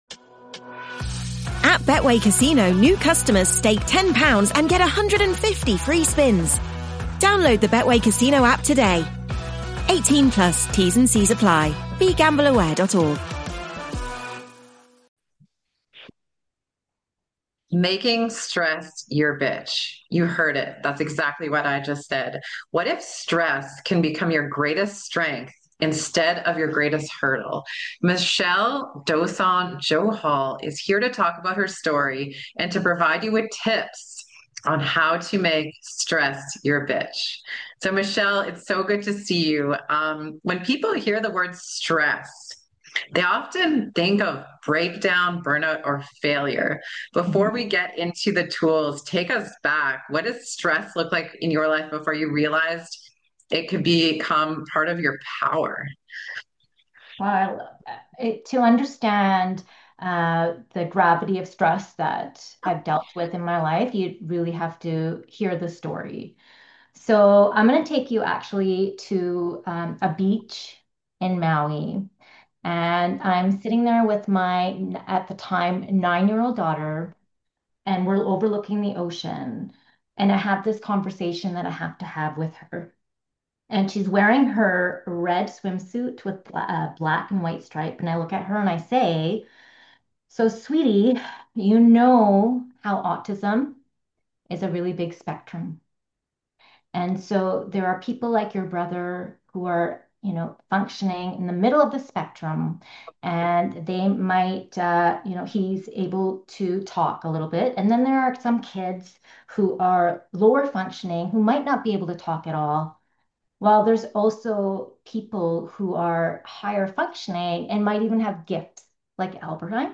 If you’re overwhelmed, burnt out, or stuck in survival mode, this conversation will help you reset your nervous system, reclaim your power, and build resilience.